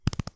Horse Gallop 5.wav